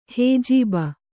♪ hējība